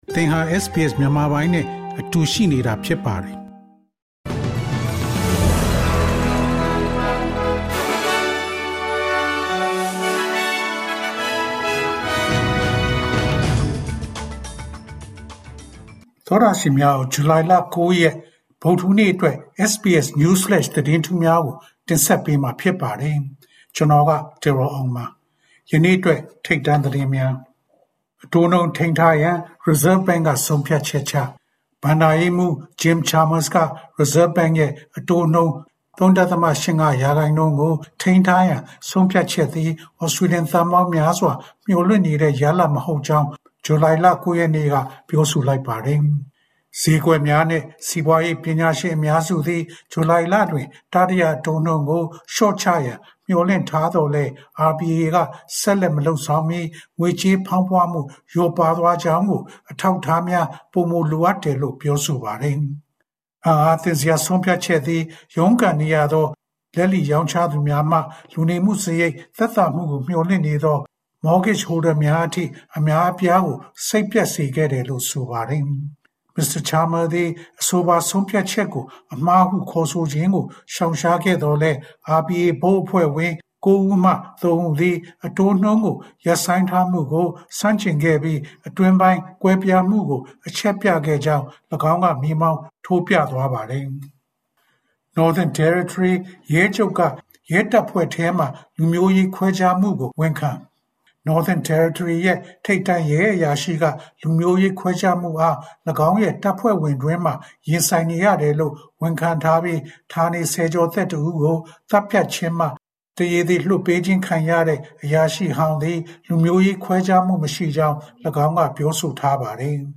SBS Burmese News Flash